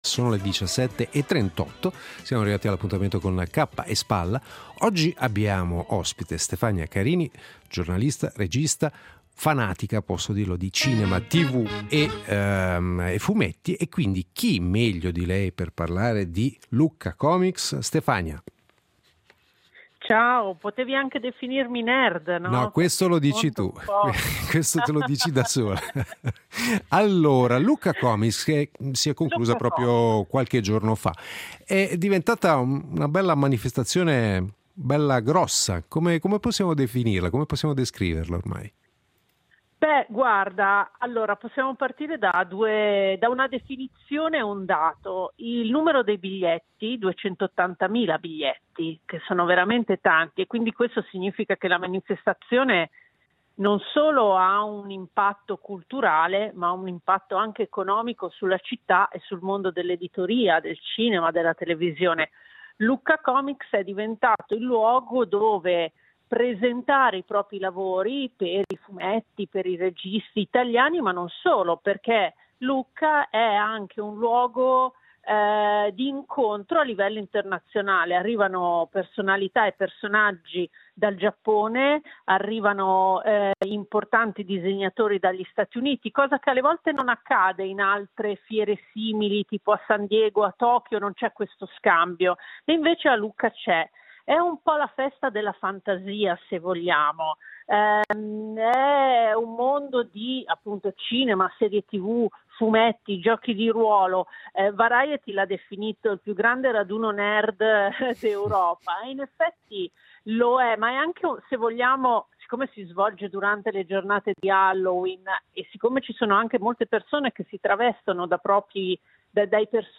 L’editoriale del giorno